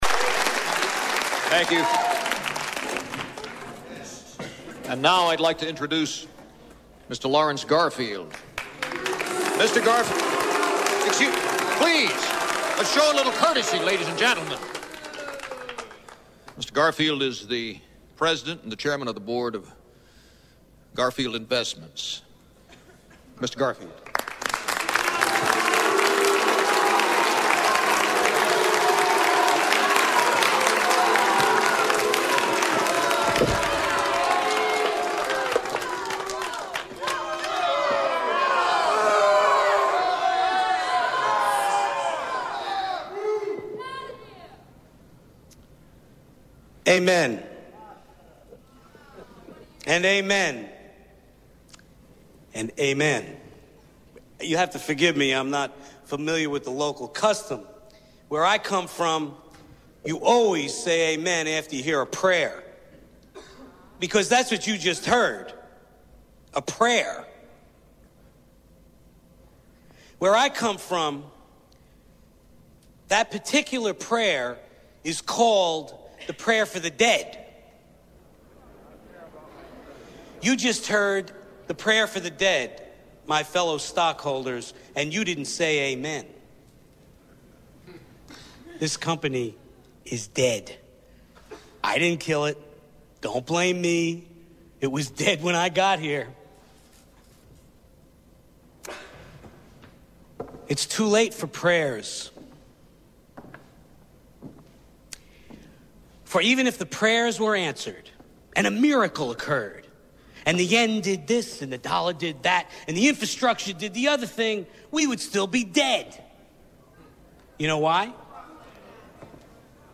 Lawrence Garfield Addresses the Stockholders